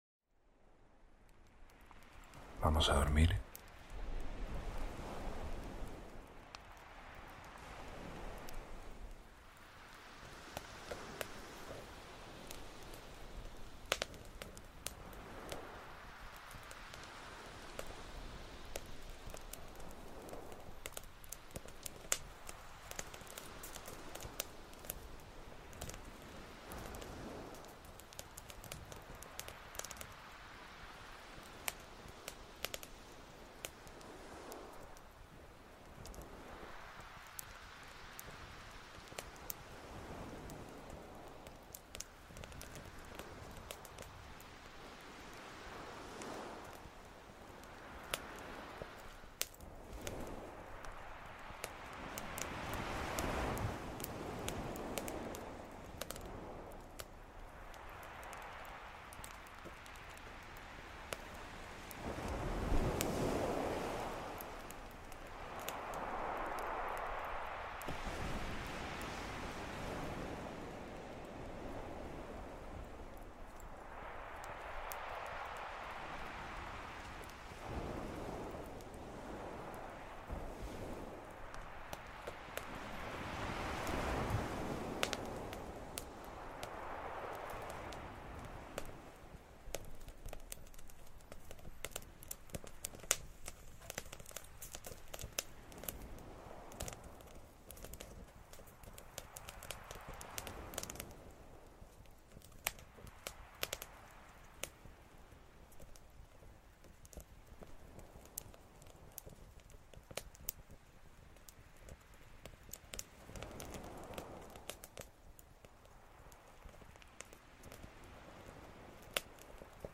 ASMR para dormir - Fogata en la playa